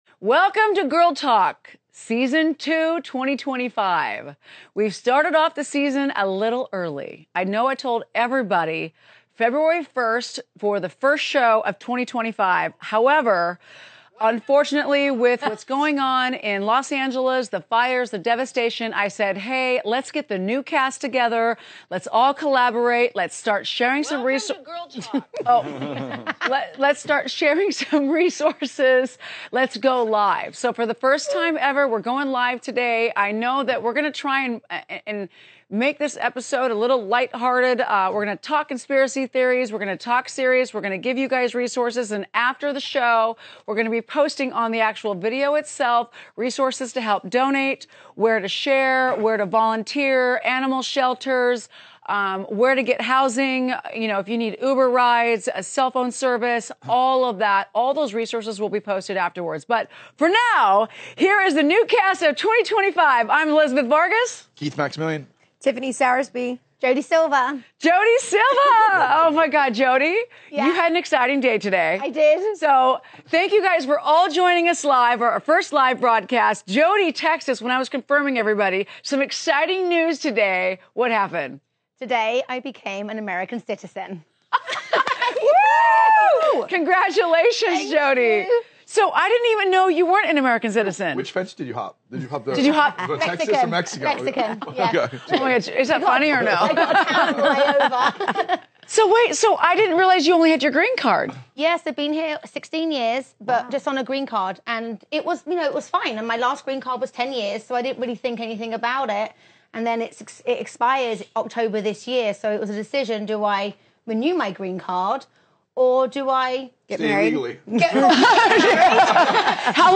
Welcome to The Vibedaytime TVs freshest and most sophisticated new talk show hosted by Elizabeth Lyn Vargas, the captivating entrepreneur, philanthropist, and former Real Housewives of Orange County star.Elizabeth brings her signature wit, warmth, and fearless authenticity to every episode, sitting down with pop culture icons, Hollywood celebrities, and renowned thought leaders for real conversations that blend glamour, grit, and genuine heart.From breaking down the latest viral trends and celeb